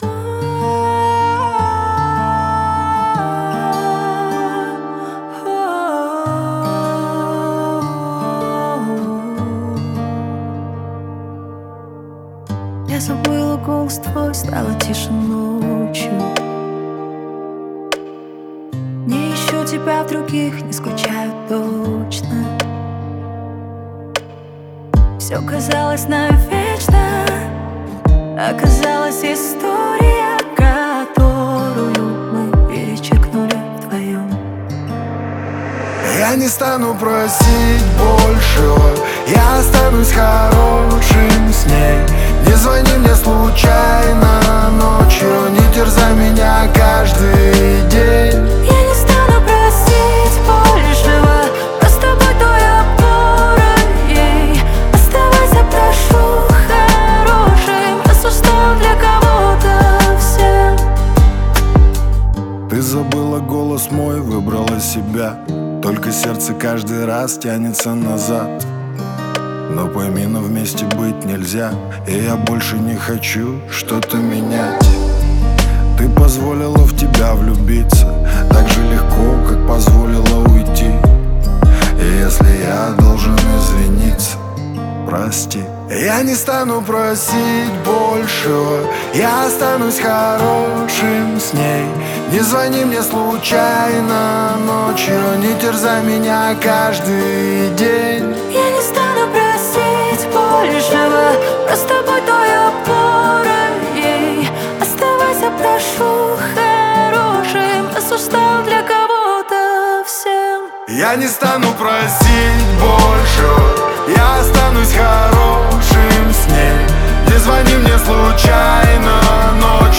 Новинки русской музыки